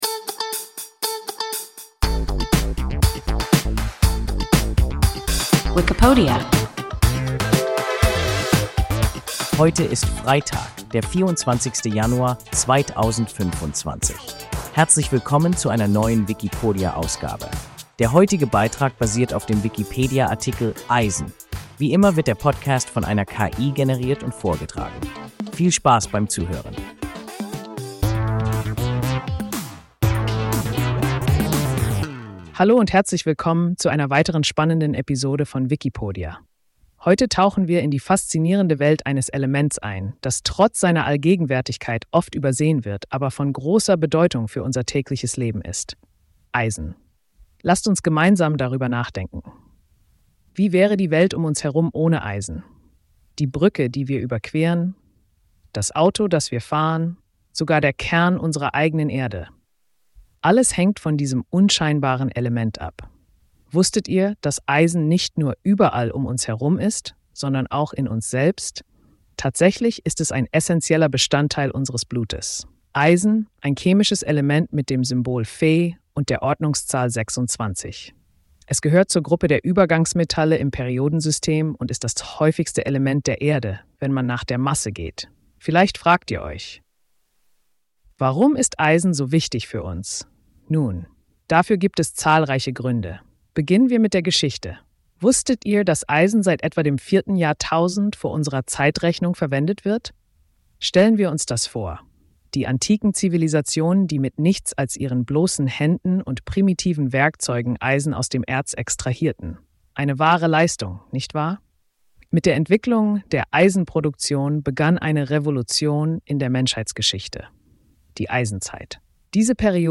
Eisen – WIKIPODIA – ein KI Podcast